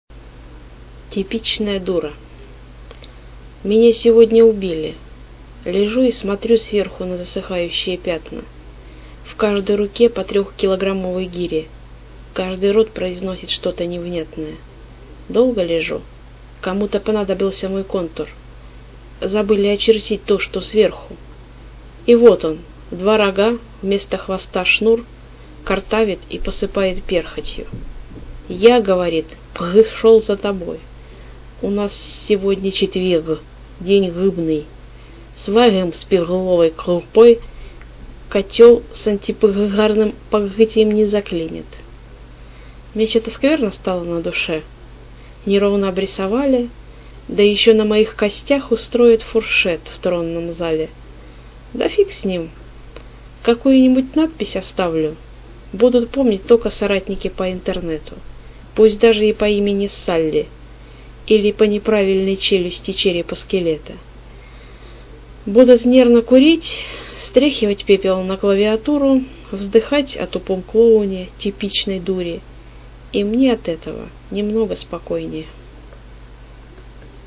И голос занудный.
А вот теперь- всё замечательно: сижу, слушаю,голос с изюминной ехиднинкой чуть-чуть, но хороший smile give_rose 16
Немного кагтавишь, а так ничего. 04 Это шоб не мучилась.